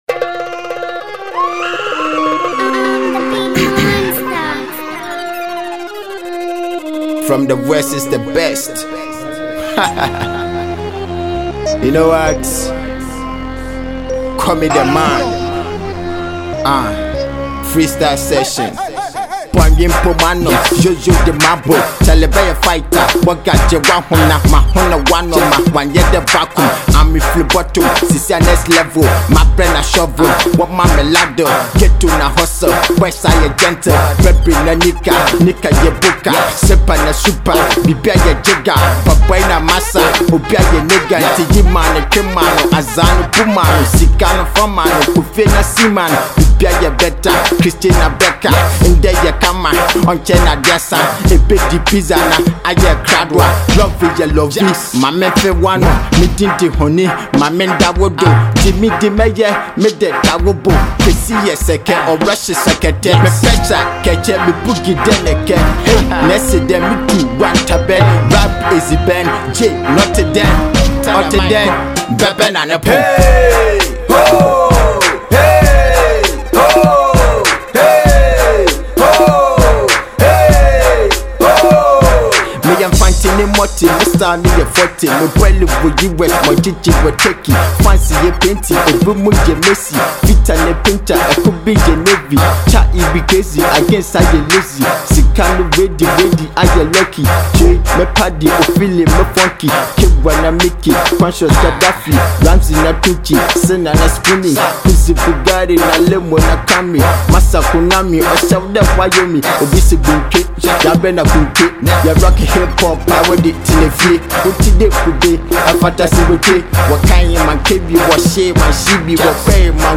Its a real Fante rap.